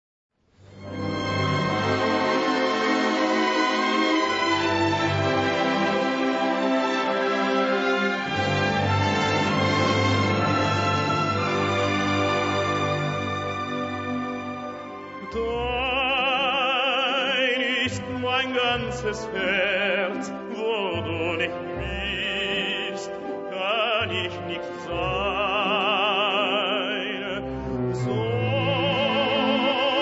• arie
• musica classica
• pianoforte